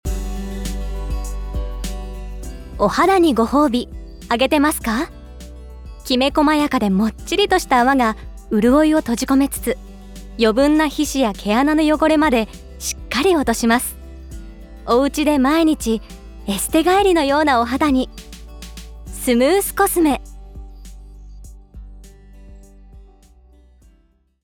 ボイスサンプル
ナレーション（化粧品ＣＭ）